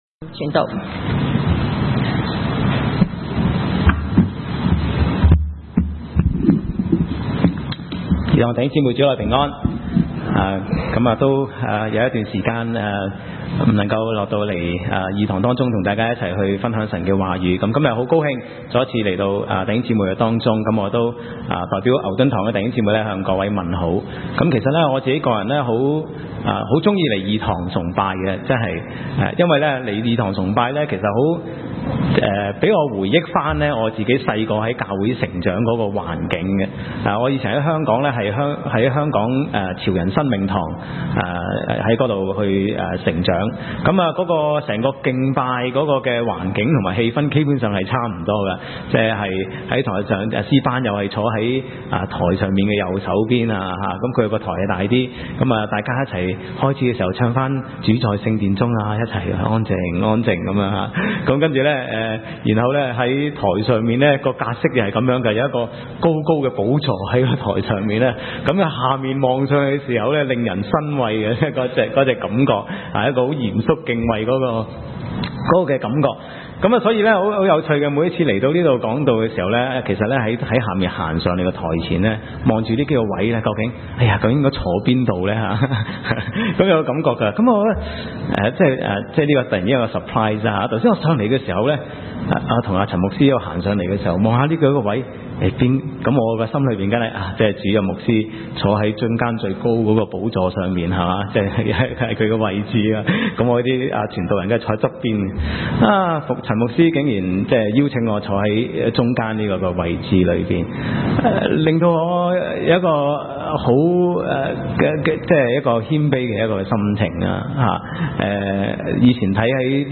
Sermon Recording